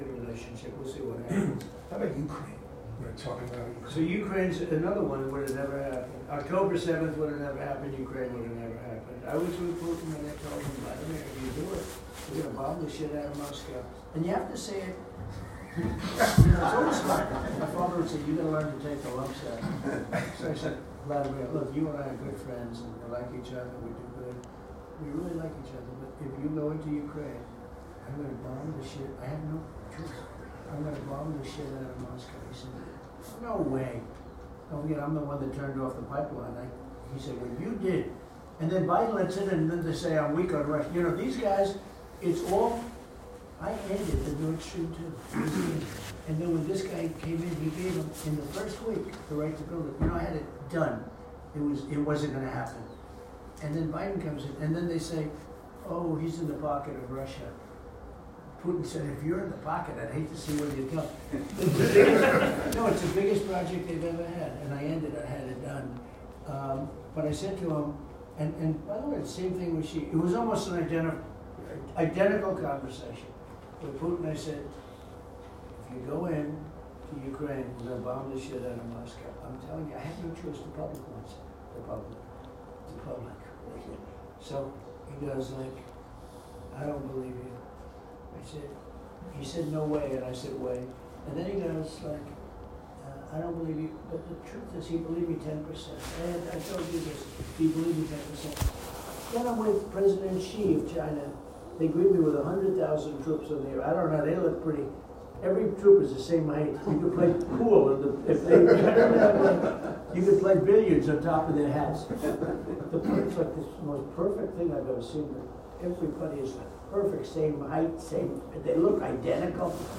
Donald Trump a declarat în 2024, la o reuniune privată a donatorilor, că a încercat odată să-l descurajeze pe președintele rus Vladimir Putin să atace Ucraina, amenințând că va „bombarda Moscova” ca represalii, conform înregistrării audio furnizate CNN.
Donald Trump dezvăluie cum l-a amenințat pe Vladimir Putin, într-o conversație cu donatorii